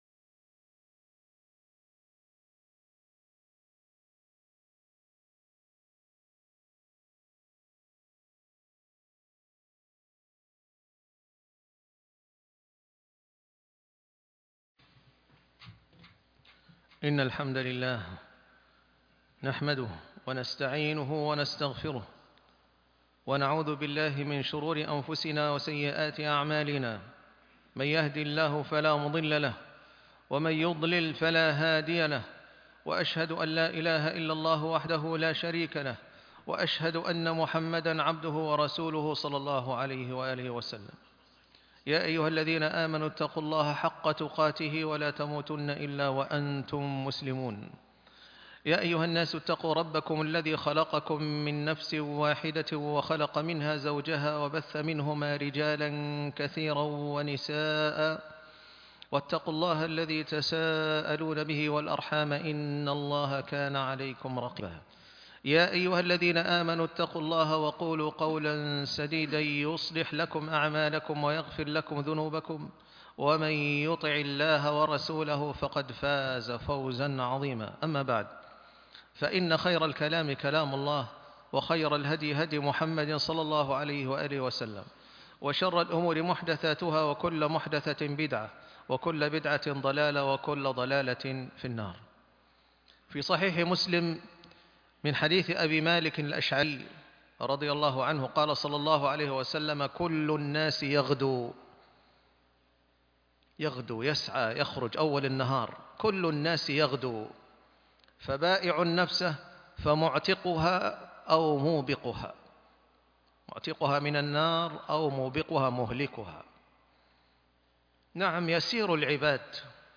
الإرتباط بالله - خطبة الجمعة